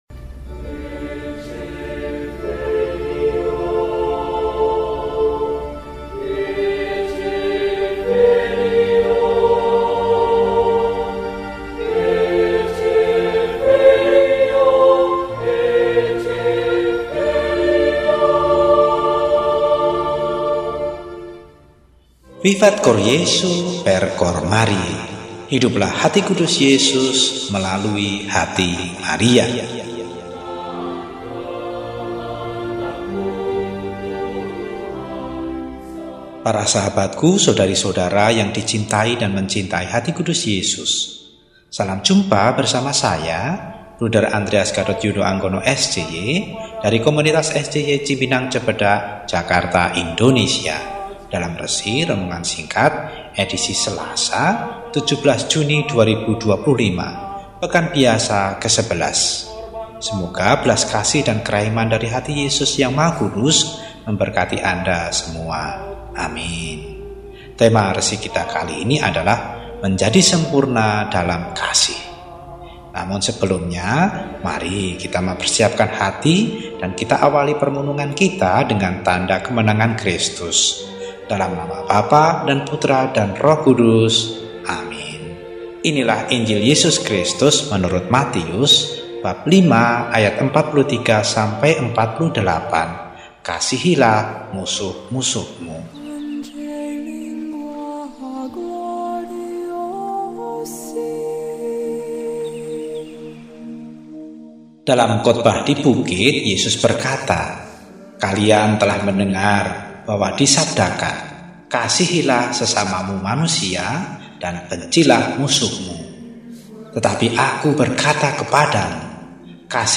Selasa, 17 Juni 2025 – Hari Biasa Pekan XI – RESI (Renungan Singkat) DEHONIAN